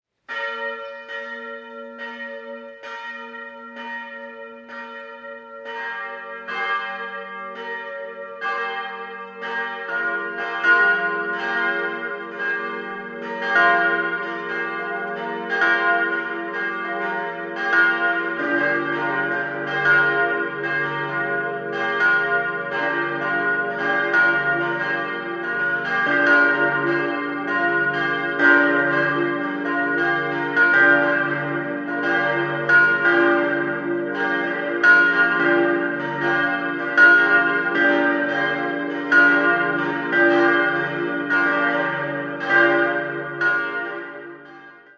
Das geräumige Gotteshaus wurde im Jahr 1932 eingeweiht. Idealquartett: d'-f'-g'-b' Die drei großen Glocken (Euphon) wurden 1949 von Karl Czudnochowsky in Erding gegossen, die kleine Glocke stammt von 1931/32 und wurde vermutlich von Lotter (Bamberg) hergestellt.